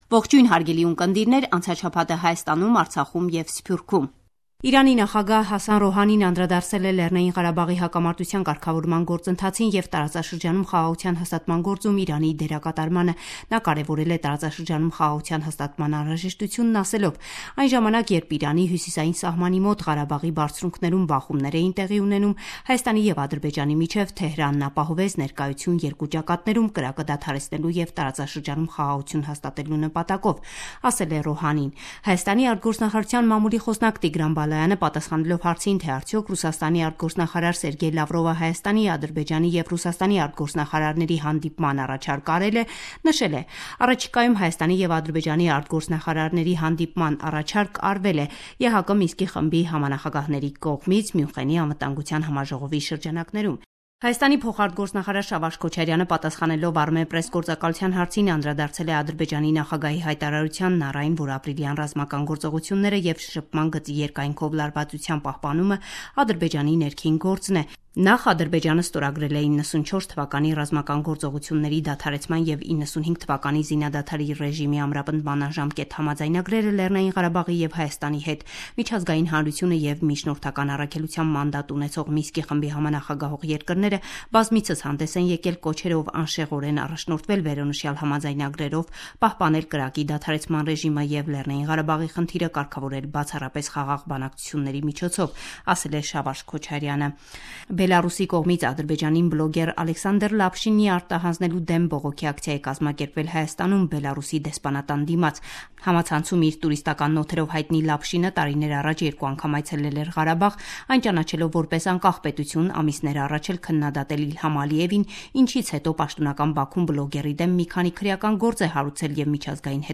Latest News – 14 February 2017